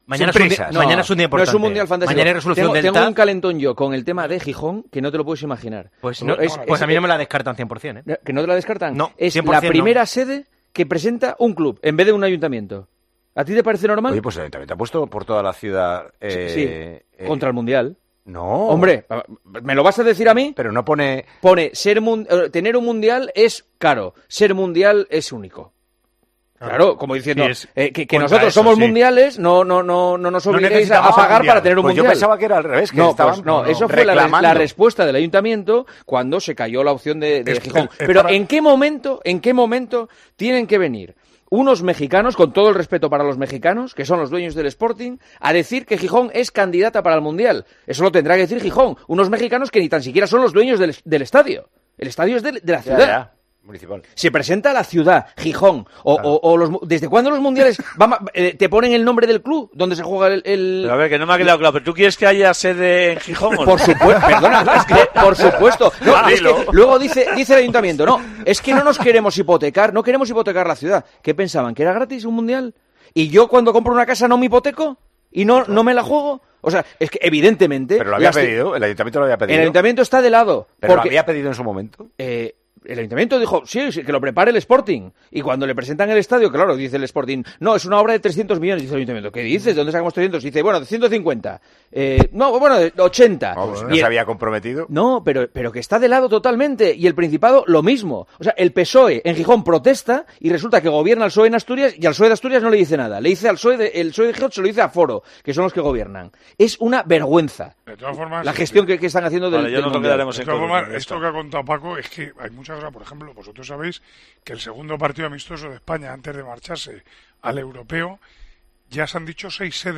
El director de El Partidazo de COPE criticó la decisión del Ayuntamiento de Gijón de decir 'no' al Mundial 2030.
La decisión del Ayuntamiento de Gijón de decir 'no' al Mundial 2030 ha generado críticas en El Partidazo de COPE. En la noche del miércoles, tras la clasificación del Real Madrid para los cuartos de final de la Champions League, Juanma Castaño criticó la decisión del Ayuntamiento de su ciudad con respecto a la cita mundialista.